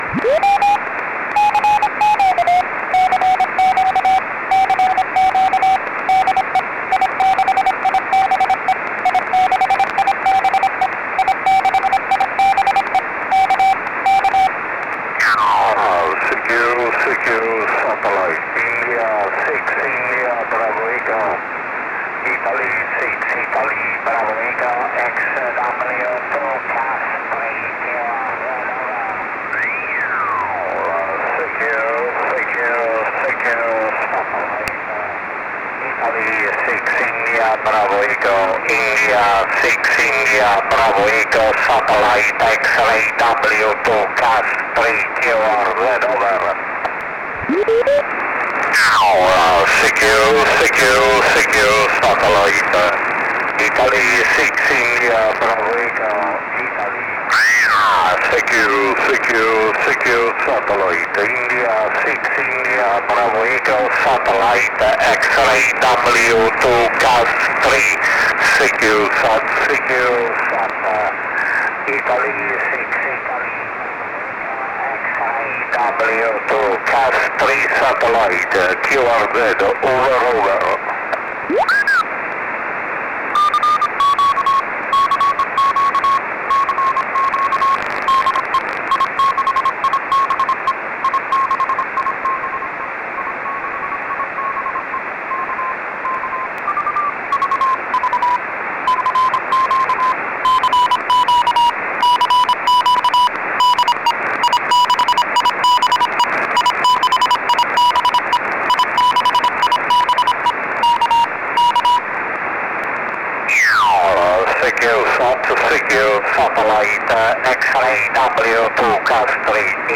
CW